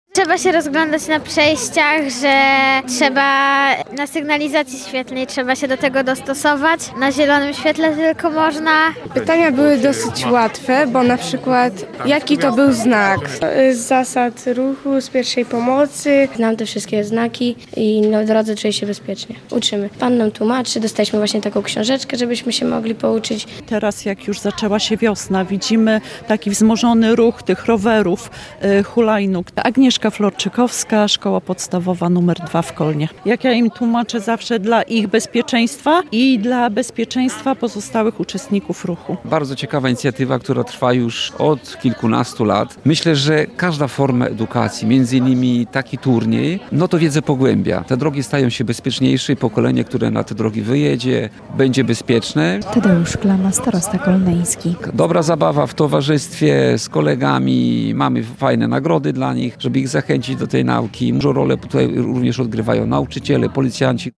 W I Liceum Ogólnokształcącym im. Adama Mickiewicza w Kolnie odbył się etap powiatowy Ogólnopolskiego Turnieju Bezpieczeństwa Ruchu Drogowego.